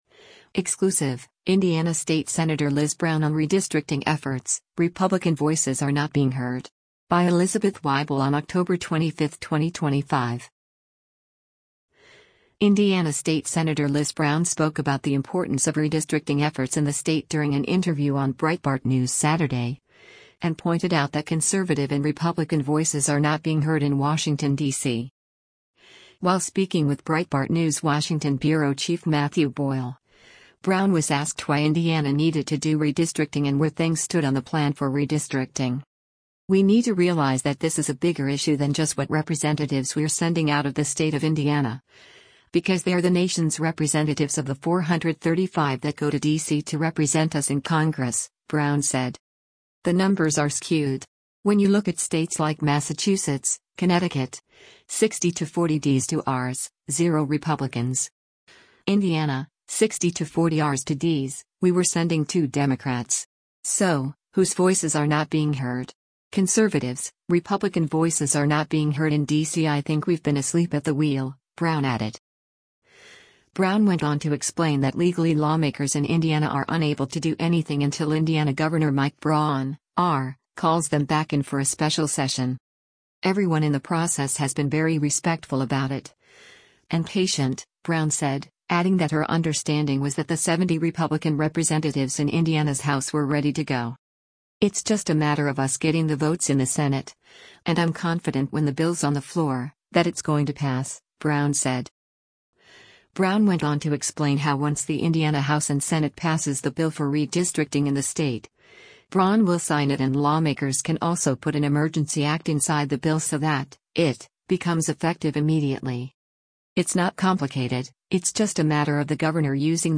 Indiana state Sen. Liz Brown (R) spoke about the importance of redistricting efforts in the state during an interview on Breitbart News Saturday, and pointed out that conservative and Republican voices “are not being heard” in Washington, D.C.